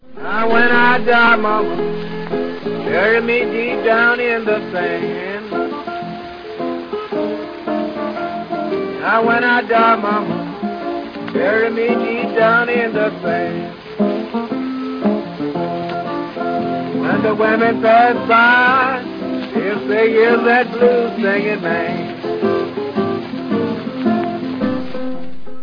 партия гитары